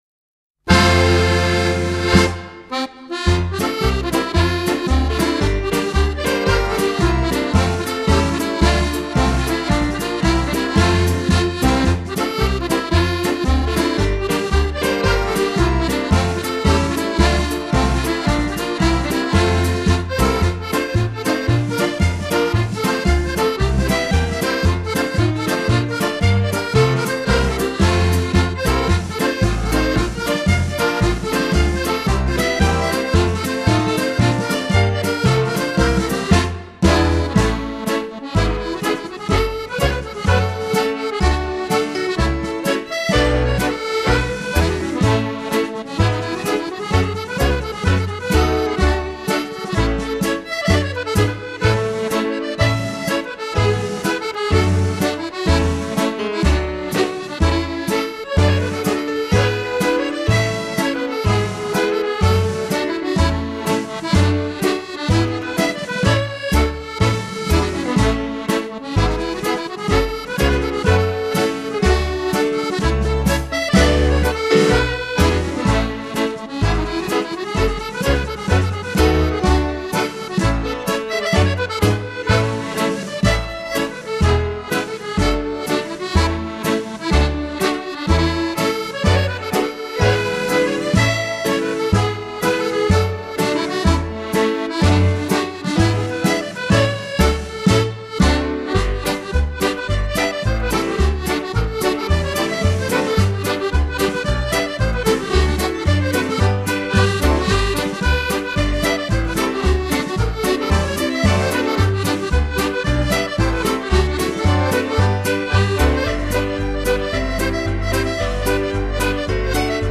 A medley